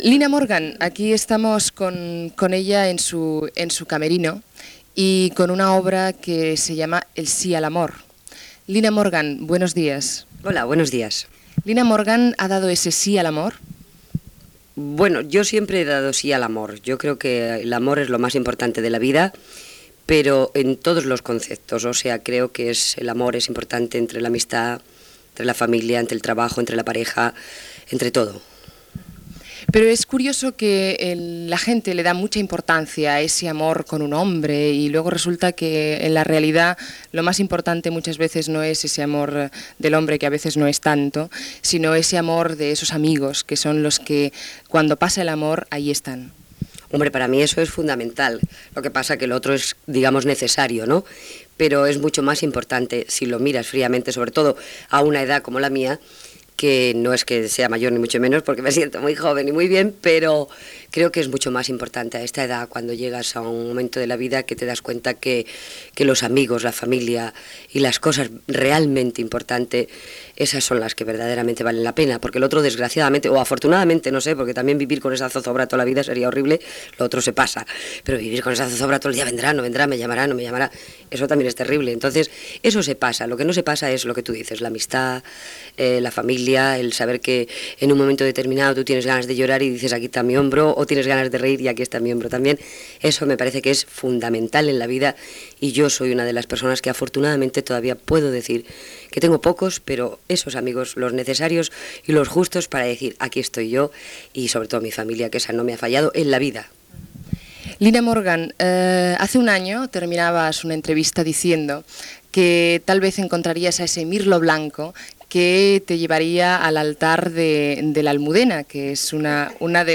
Entrevista a l'actriu Lina Morgan al seu camerino on representa "Sí al amor" al Teatre Apolo de Barcelona.
Info-entreteniment